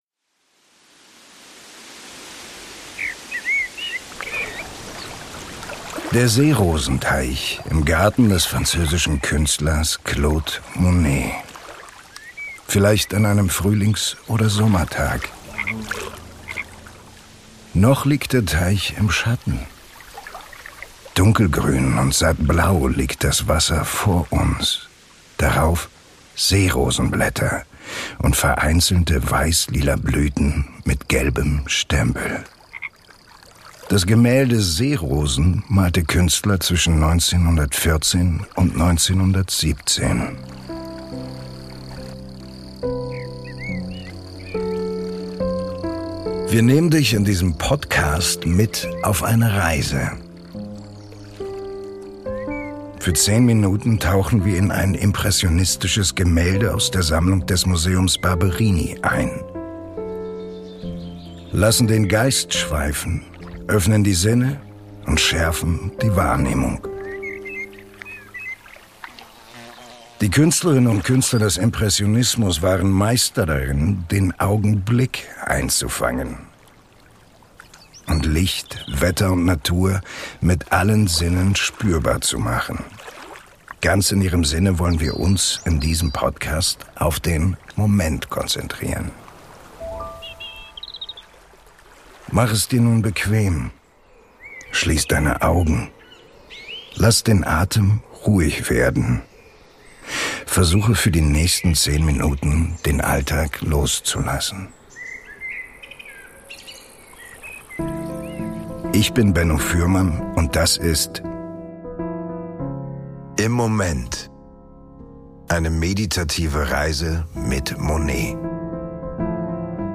Eine meditative Reise mit Monet